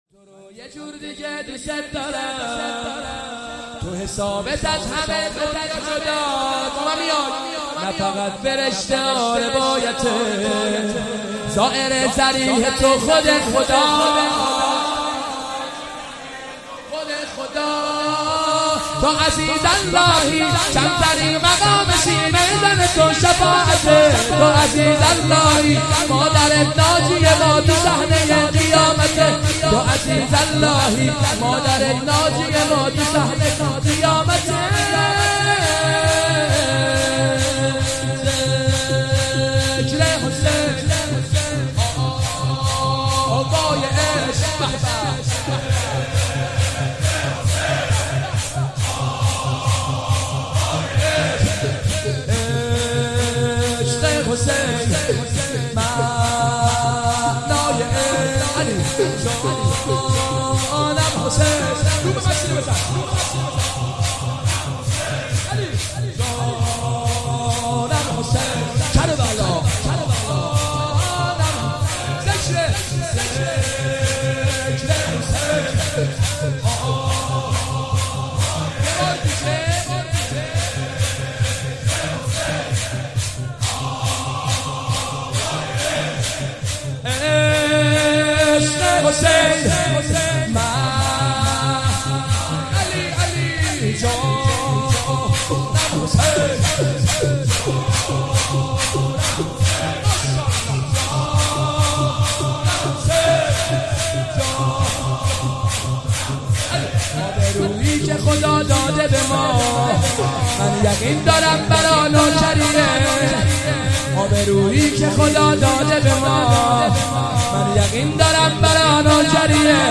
مداحی جدید
شب هفتم محرم۹۷ هیئت روضة‌ العباس
شور